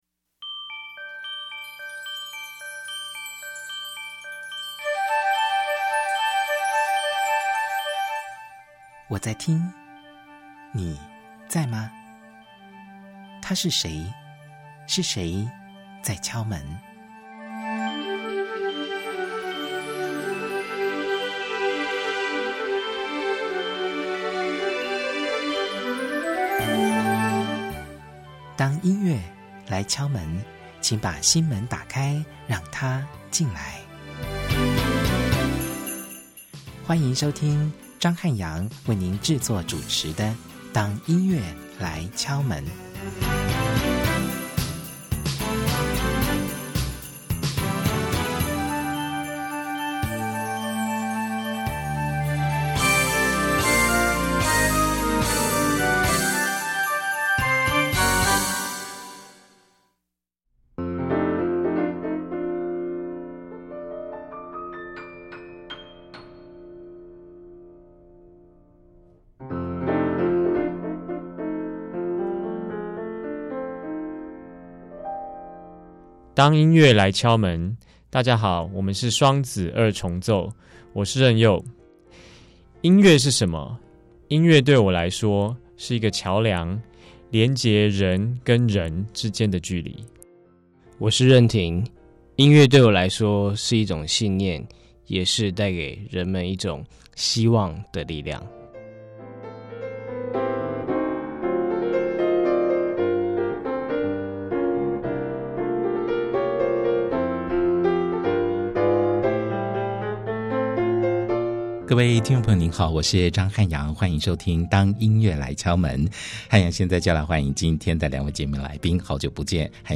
第一單元~本週節目來賓是Twincussion雙子二重奏。